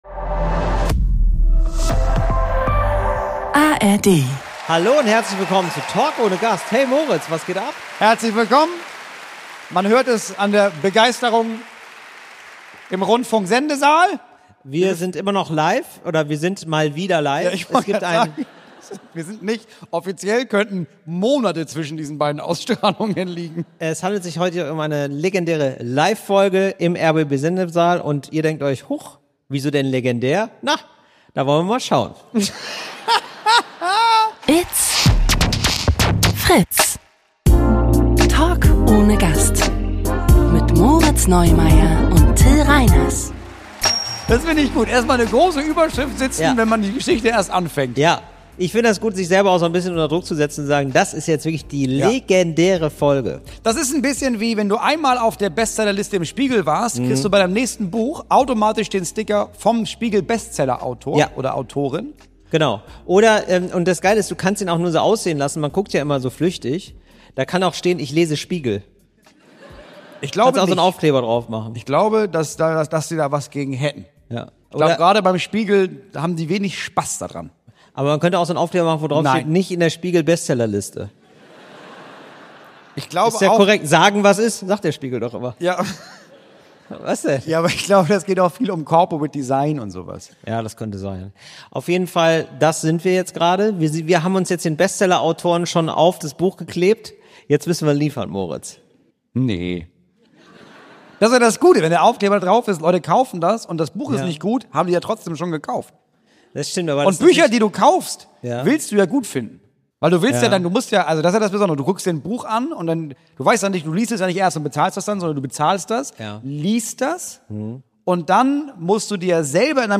Wir haben sie live im rbb Sendesaal für euch aufgenommen, liebevoll konserviert, und jetzt machen wir sie endlich gemeinsam auf.